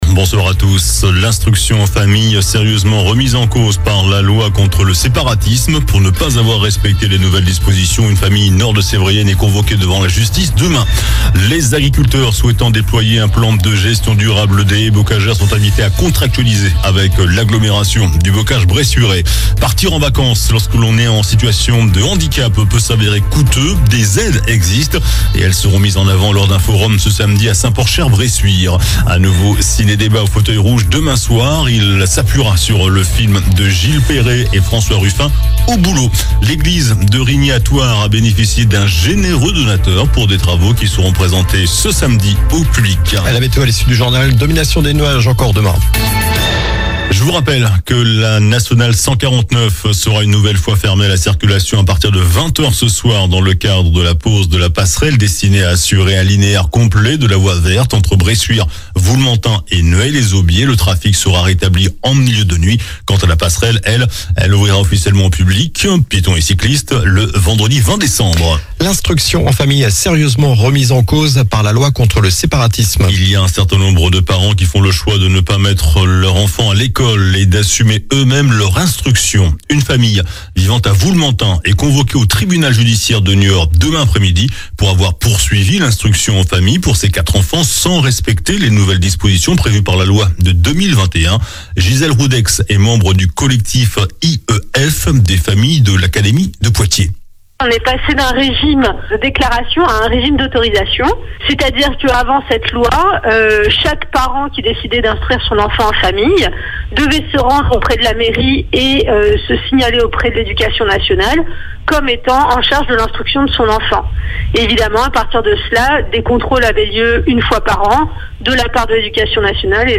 JOURNAL DU MERCREDI 27 NOVEMBRE ( SOIR )